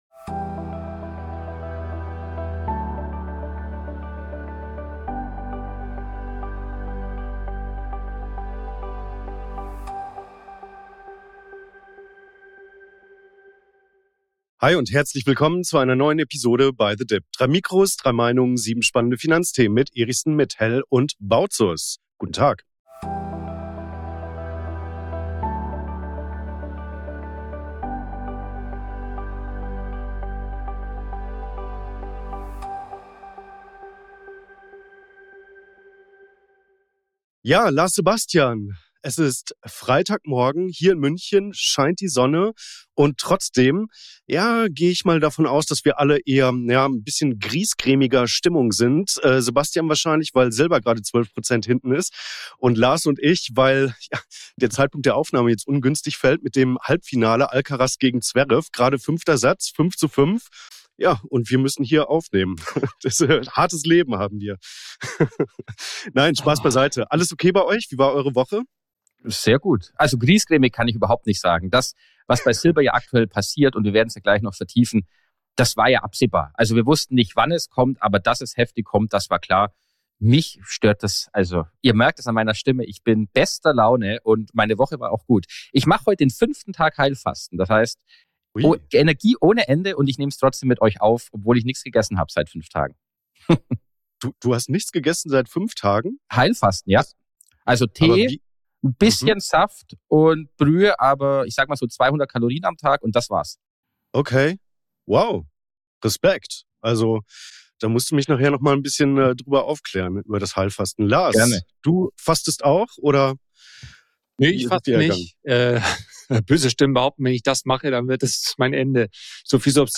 Beschreibung vor 2 Monaten Auch diese Woche begrüßen wir euch unter dem Motto „3 Mikrofone, 3 Meinungen“ zu den folgenden Themen in dieser Ausgabe: Die unsichtbare Geldvernichtung!